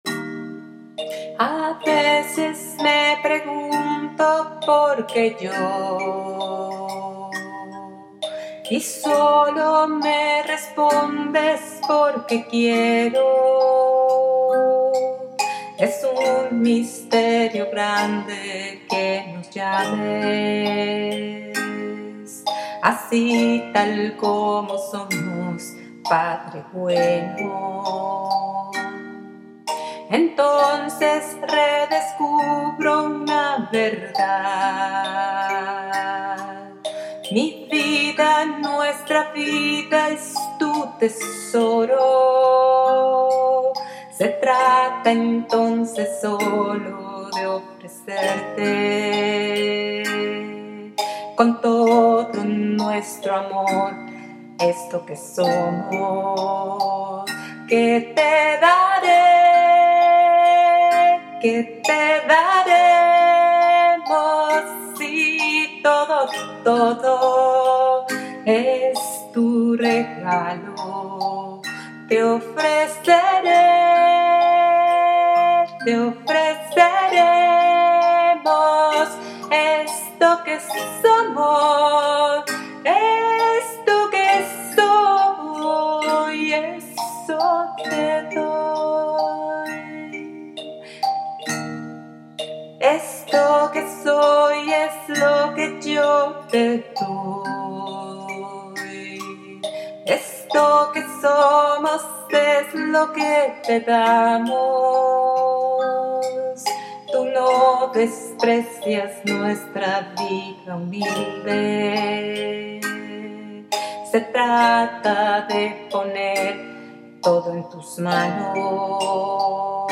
CANCION, Reflexion